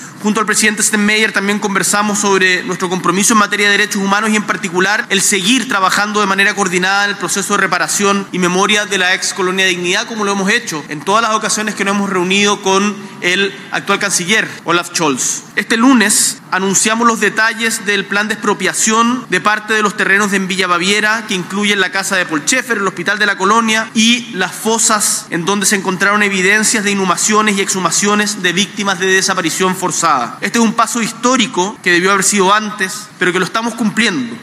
Posteriormente, en una declaración conjunta, el presidente Boric destacó el anuncio del plan de expropiación de terrenos en Villa Baviera, calificándolo como “un paso histórico” para transformar uno de los lugares más oscuros de la represión en un sitio de memoria y reflexión para que nunca más se repitan esos hechos en Chile.